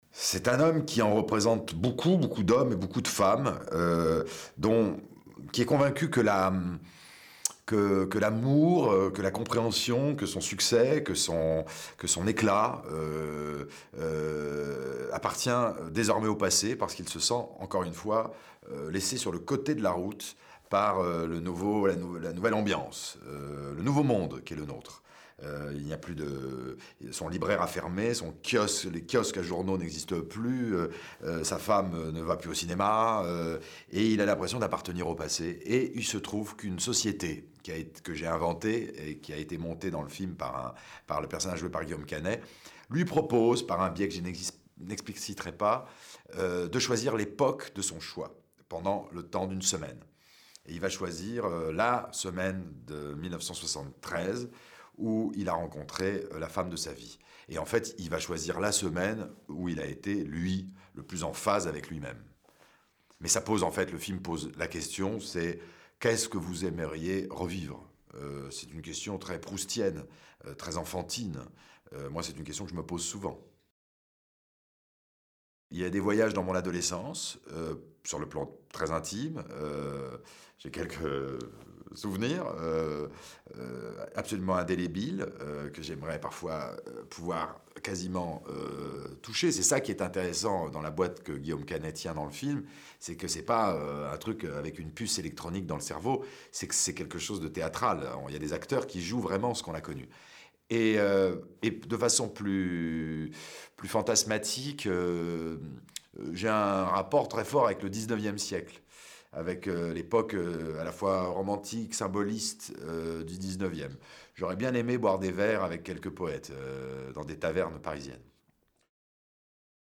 DSZUL_Interview_Nicolas_Bedos.mp3